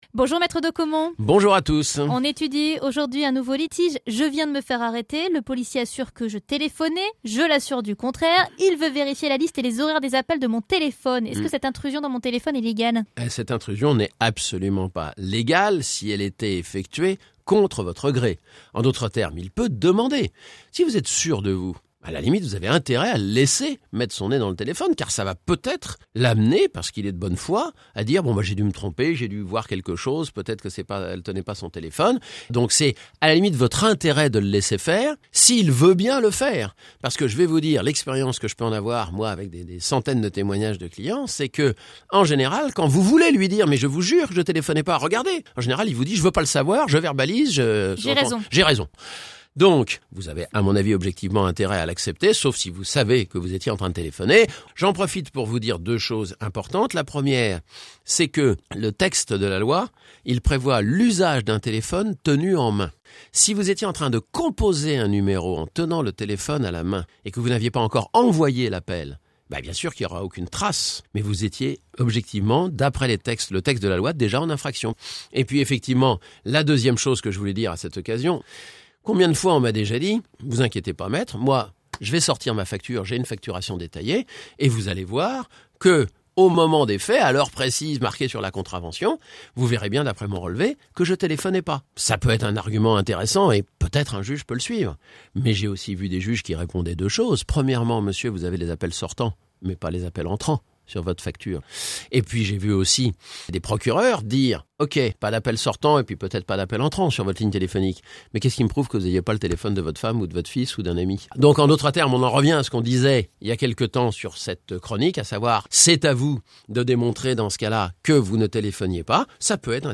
Chronique du 09/12/2012 – Téléphone au volant